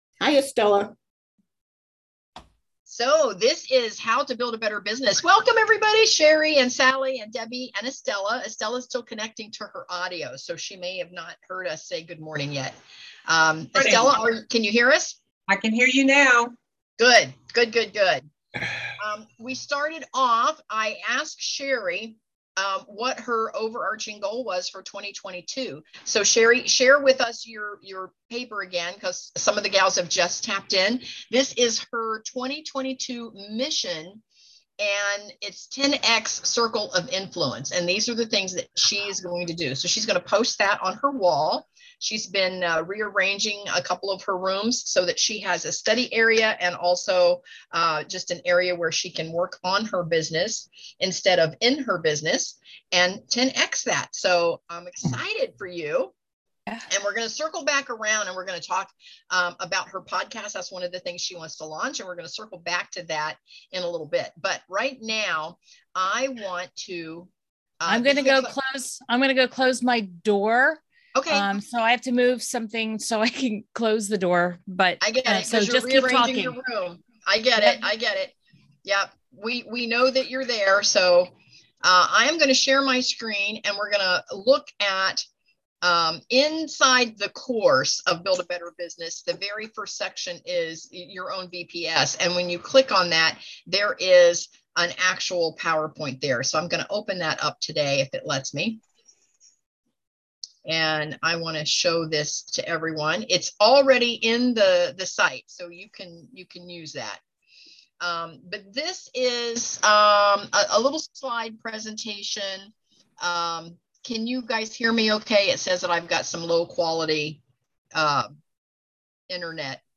This is our first mastermind zoom call of 2022.